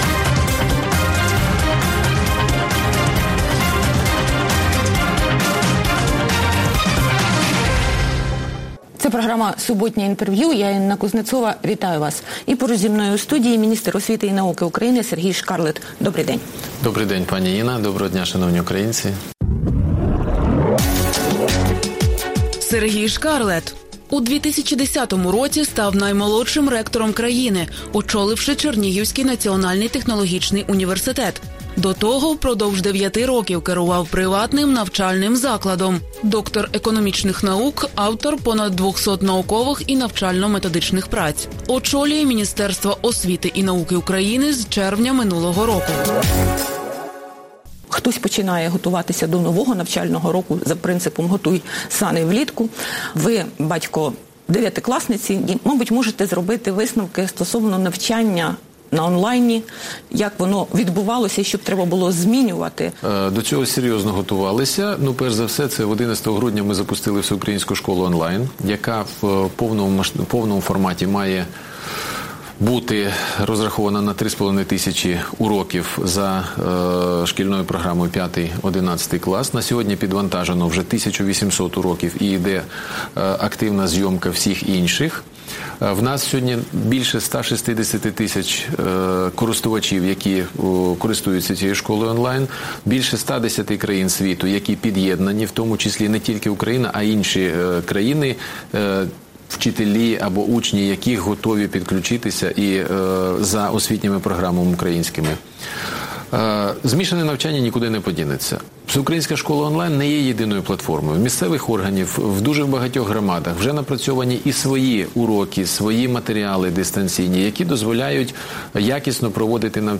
Суботнє інтерв’ю | Сергій Шкарлет, міністр освіти та науки України
Суботнє інтвер’ю - розмова про актуальні проблеми тижня. Гість відповідає, в першу чергу, на запитання друзів Радіо Свобода у Фейсбуці